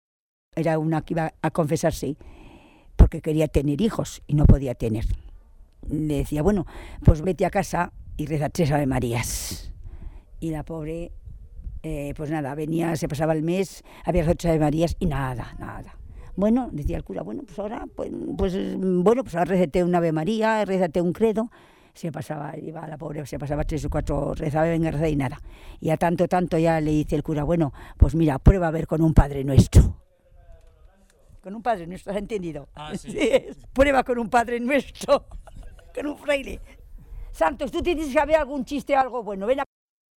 Clasificación: Cuentos
Lugar y fecha de recogida: El Redal, 17 de julio de 2003